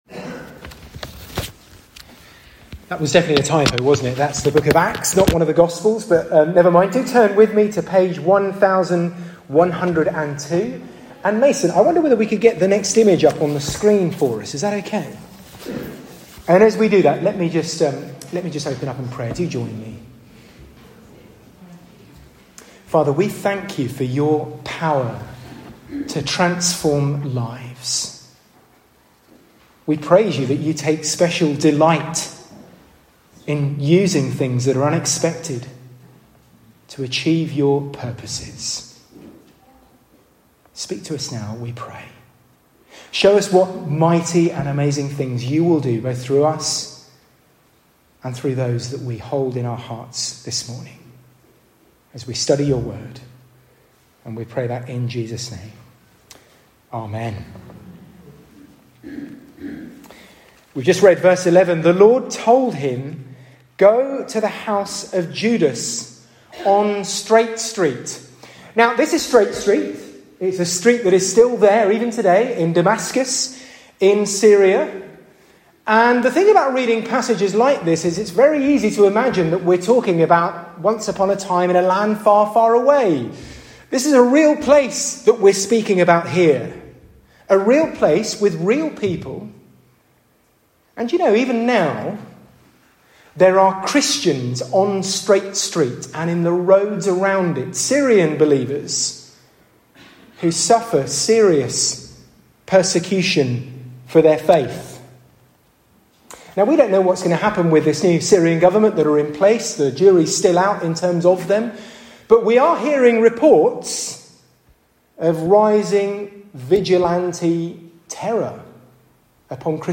SERMON-2ND-FEBRUARY.mp3